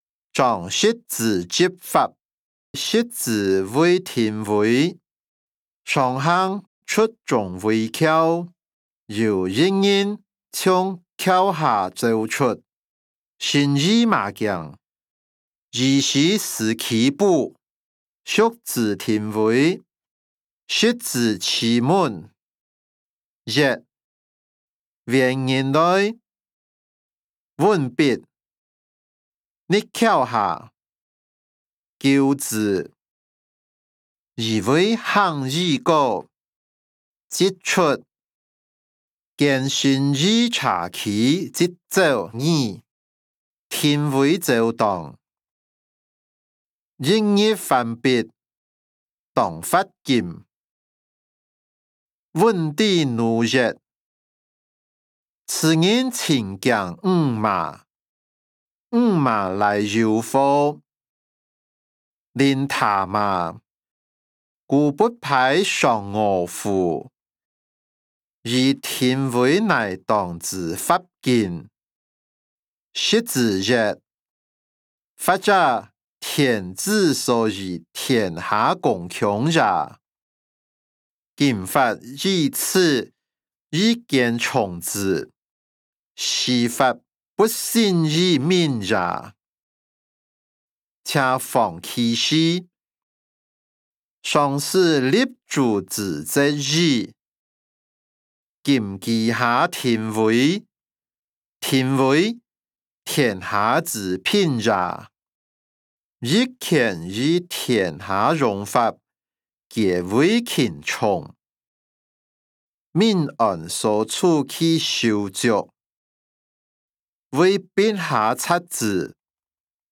歷代散文-張釋之執法音檔(饒平腔)